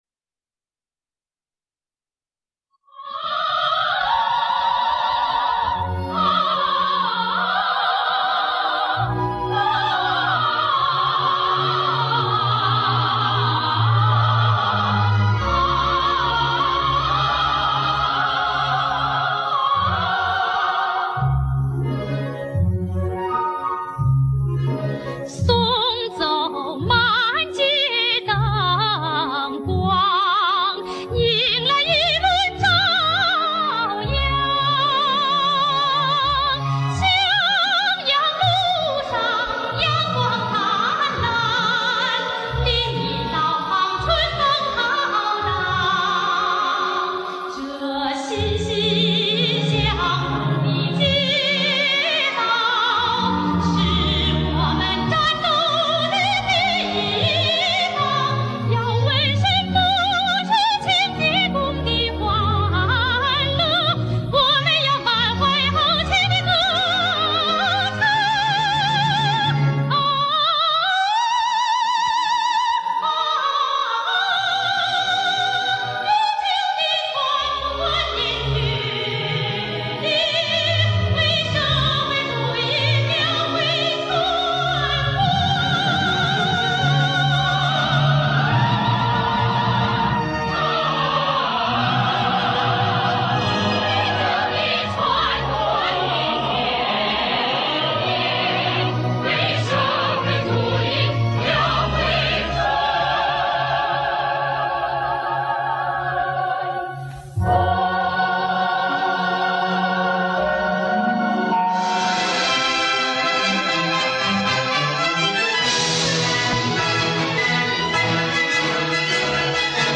【电影歌曲】